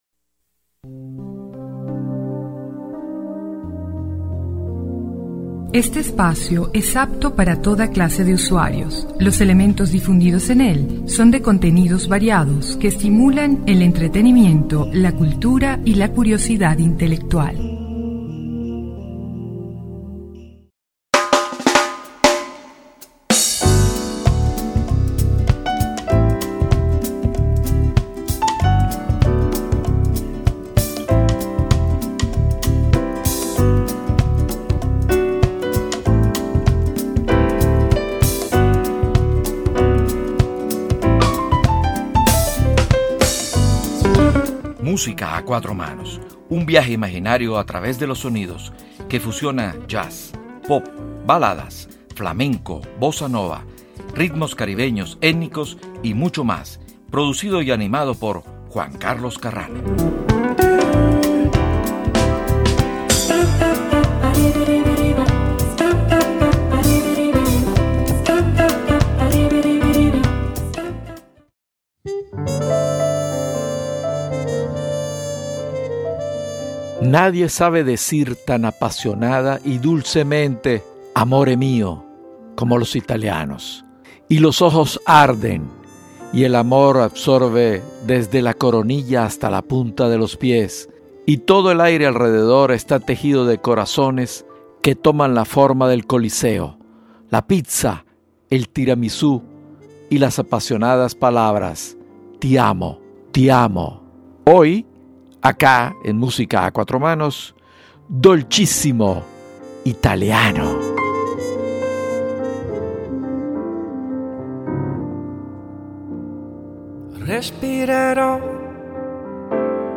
En este nuevo episodio, hemos seleccionado un grupo de canciones que no son Jazz, pero que con arreglos exquisitos e interpretaciones magistrales, se van convirtiendo en JAZZ, y entonces podrán decir……esto también es JAZZ.